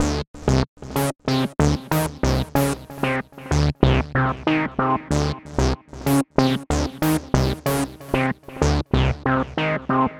Monologue-01
chord sound effect free sound royalty free Memes